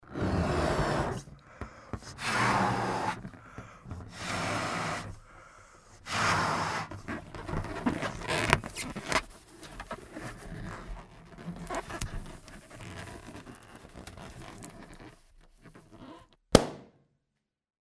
Шарик надули завязали и он лопнул